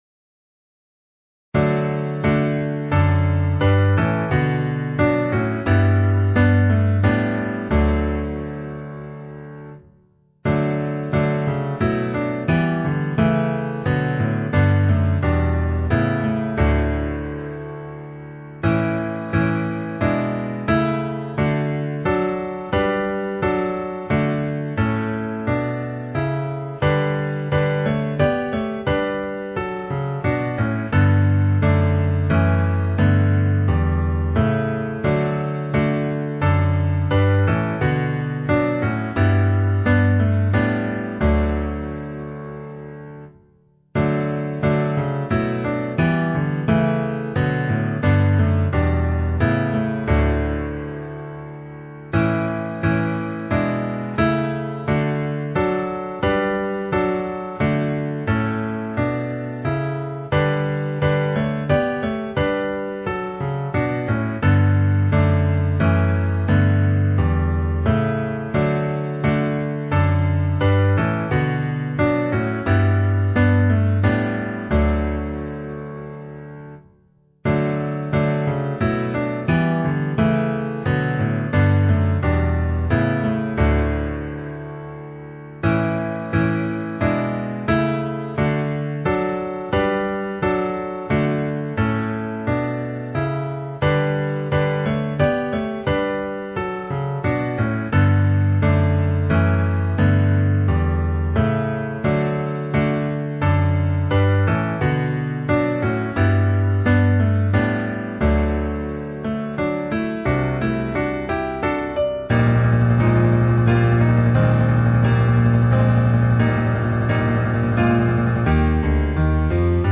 • Composer: Irish melody,
• Key: E♭